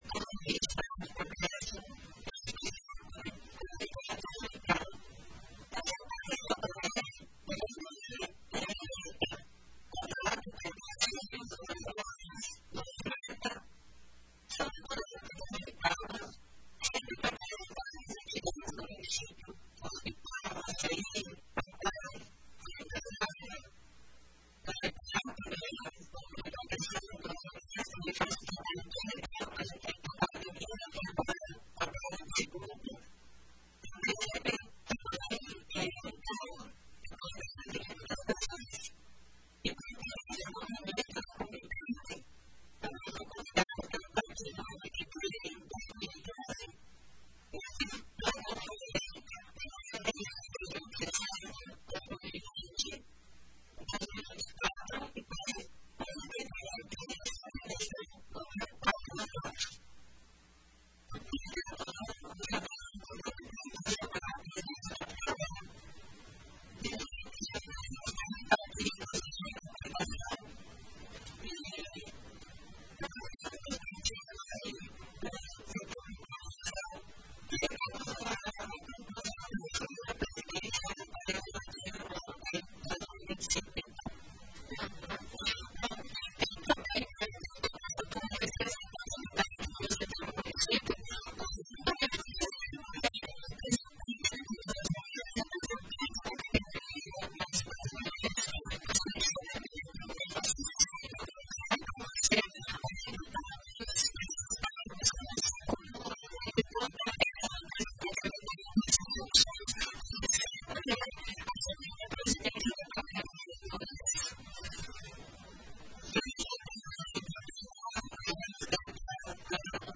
Abaixo você confere a entrevista completa: